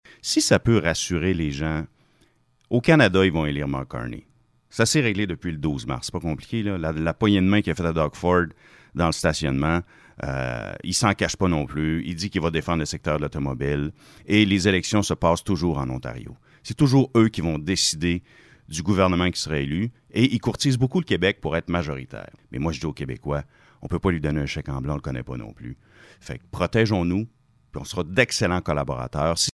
était en entrevue lundi matin sur les ondes de M105 dans le cadre d’une série d’entretiens qui seront réalisés dans les prochains jours.